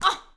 SFX_Battle_Vesna_Defense_07.wav